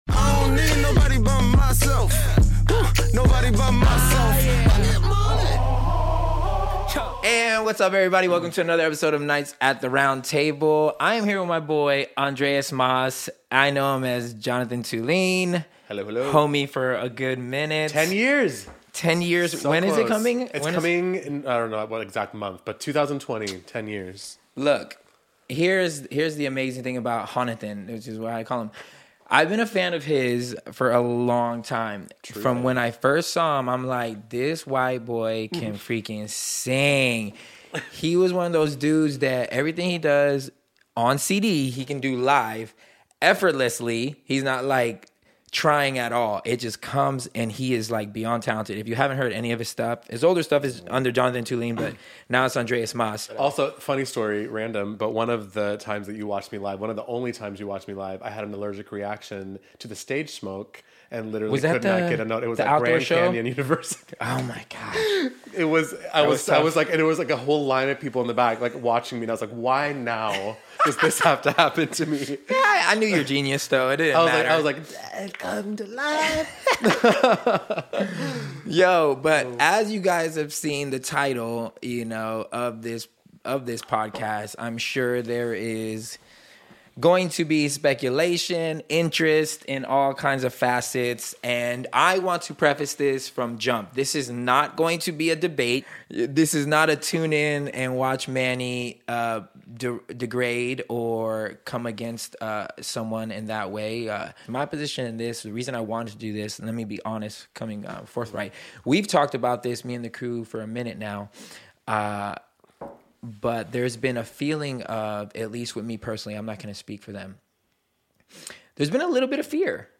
Like it or not this conversation has to happen.